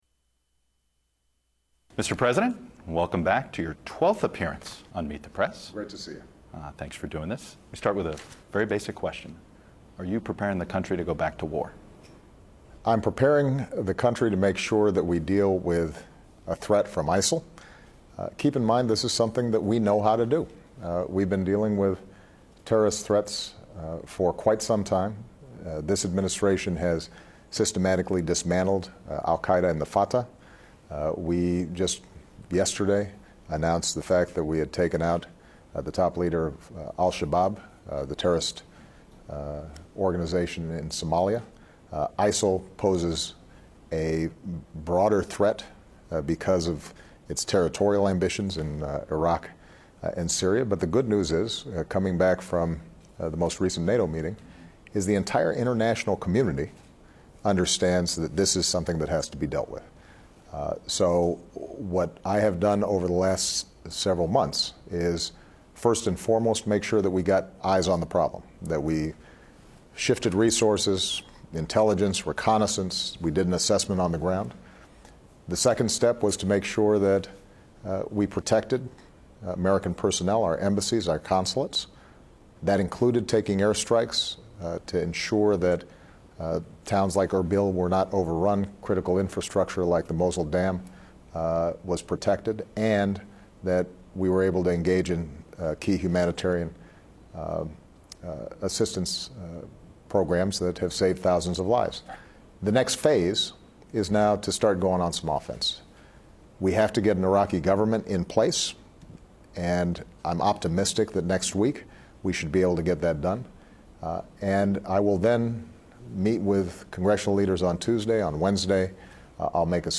U.S. President Barack Obama is interviewed by the new host of "Meet the Press," Chuck Todd